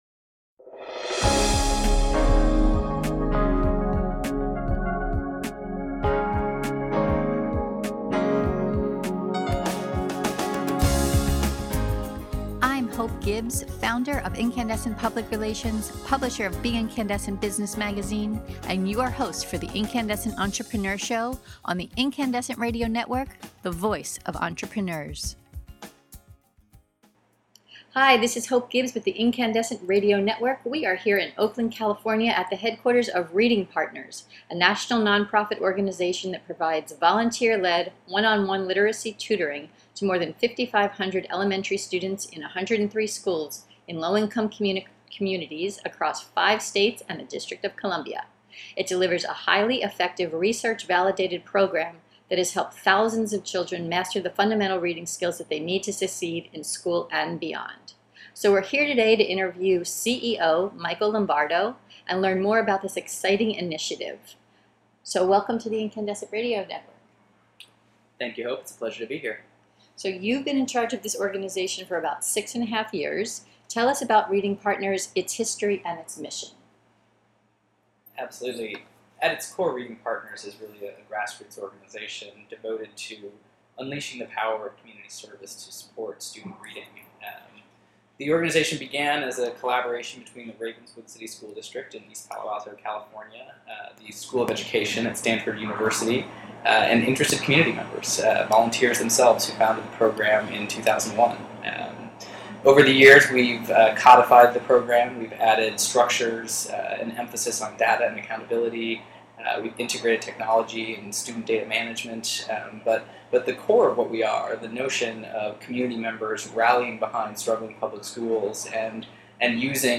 In this podcast interview we learn: The history, goals and mission of Reading Partners.